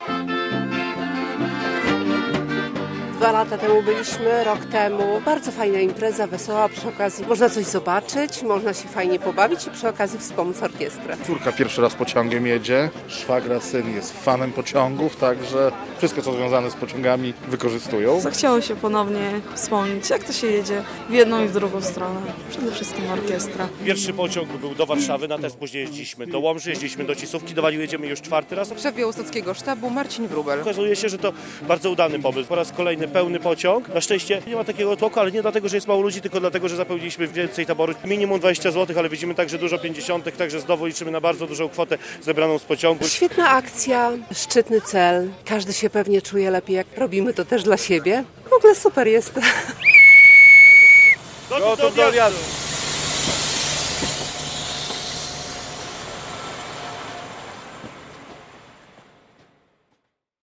Orkiestrowy pociąg do Walił - relacja